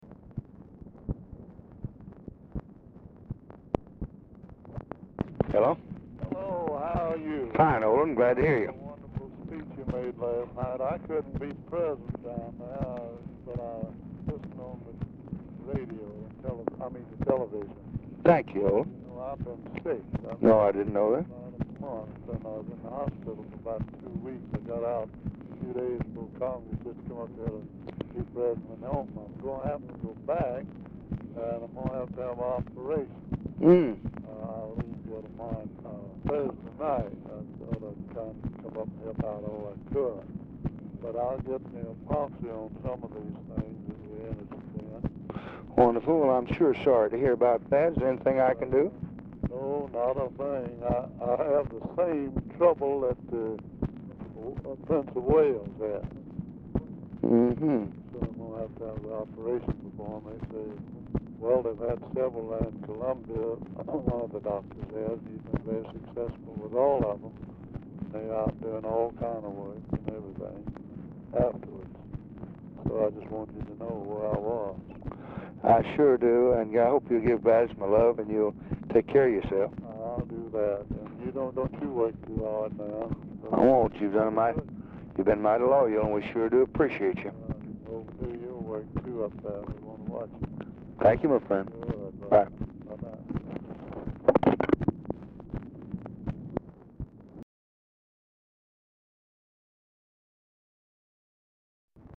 Telephone conversation # 6707, sound recording, LBJ and OLIN JOHNSTON, 1/5/1965, 4:55PM
Format Dictation belt
Location Of Speaker 1 Oval Office or unknown location